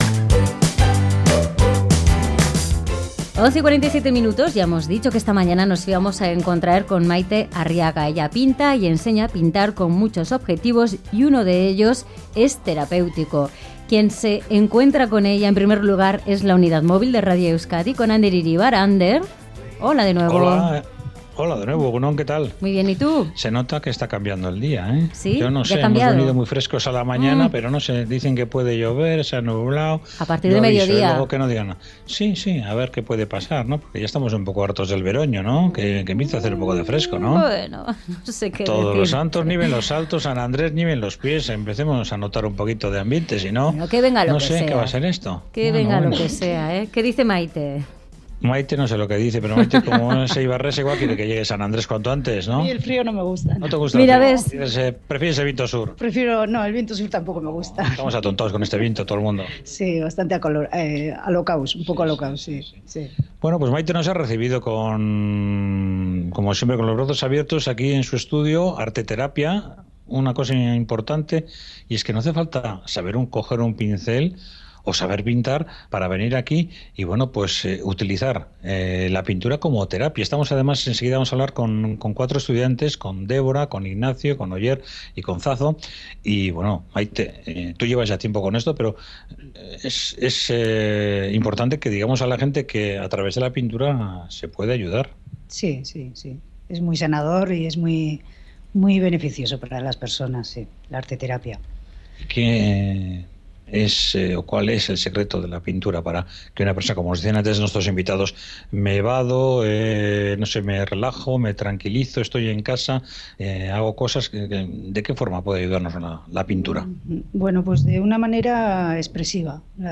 Unidad Móvil en el estudio de Arteterapia